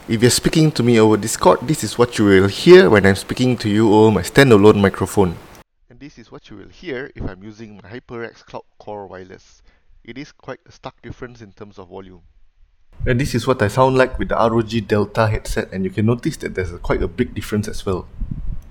Low mic volume
The mic sensitivity is a bit on the lower side of things, and my Discord mates complained that I sounded muffled and distant.
I compared my BM800 condenser mic versus the HyperX Cloud Core Wireless and ROG Delta.
MicrophoneBi-directional electret condenser, detachable
HyperX-Cloud-Core-Wireless-mic-test.mp3